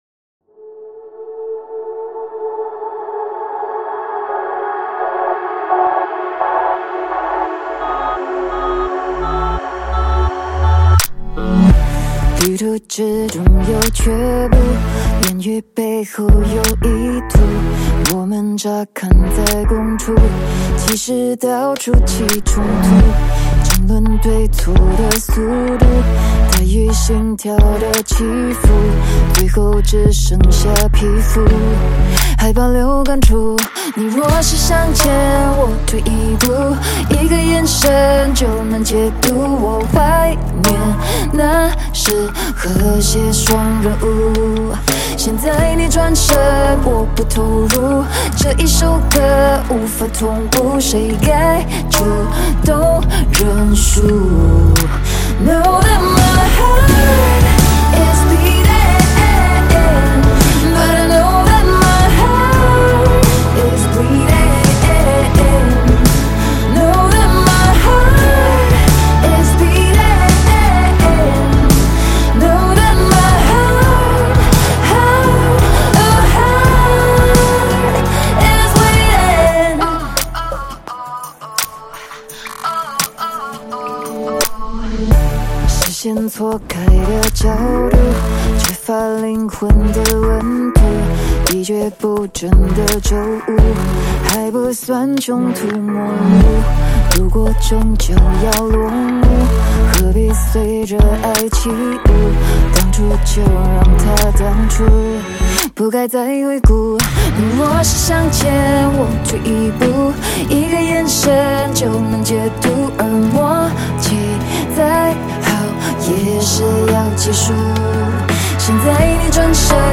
Drums Percussion
Recorded at NRG Studio, North Hollywood, CA